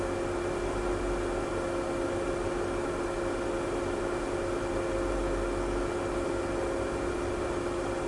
Computer Hum.wav